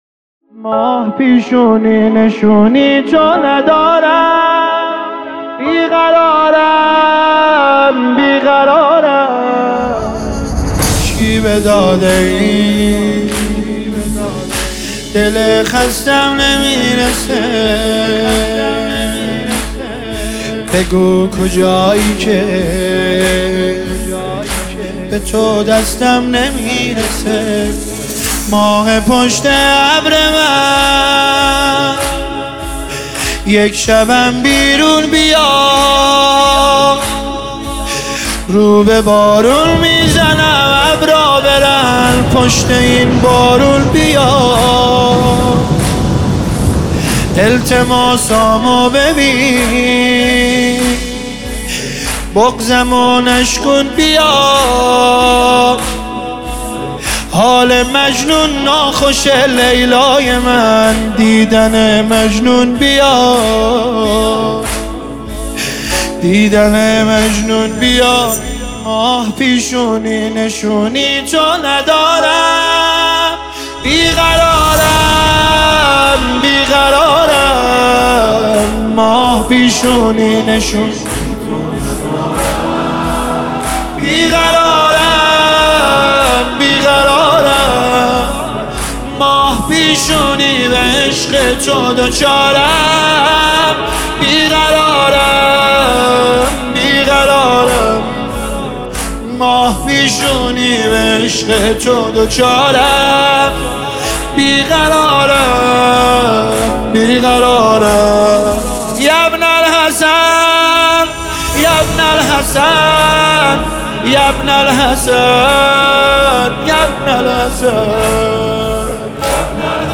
نماهنگ احساسی